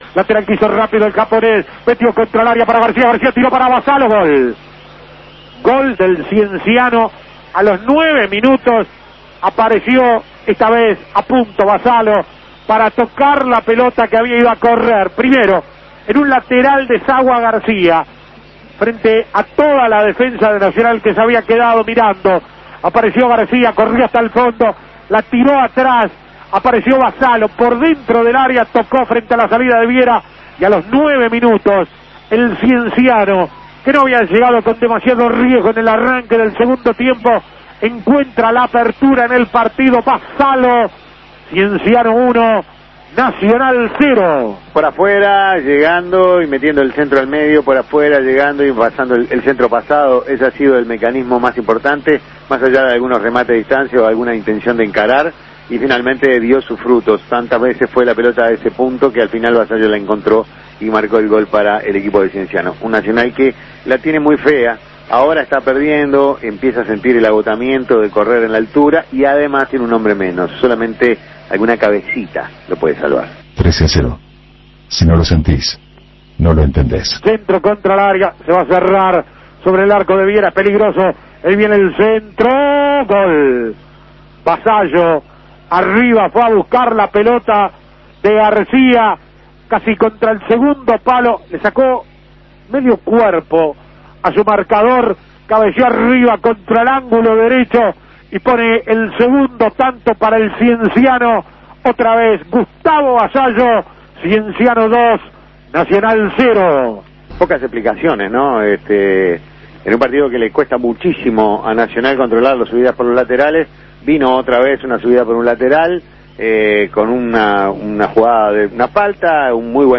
Goles y comentarios Escuche los goles de Cienciano